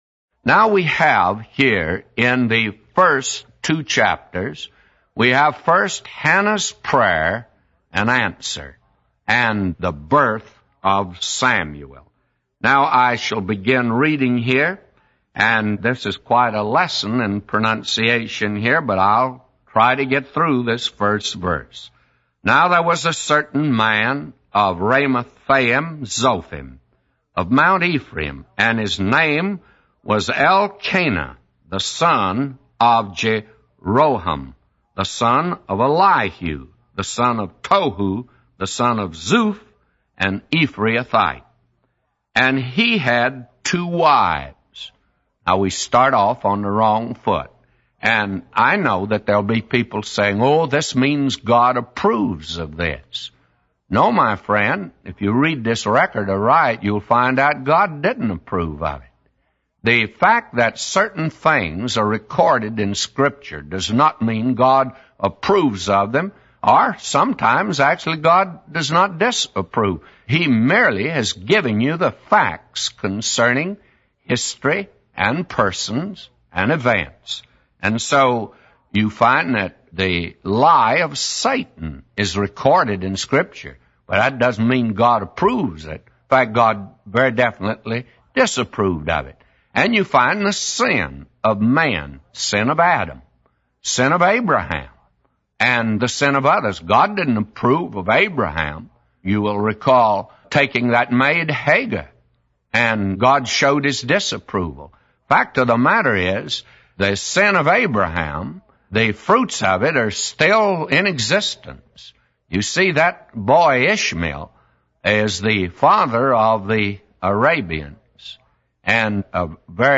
A Commentary By J Vernon MCgee For 1 Samuel 1:1-999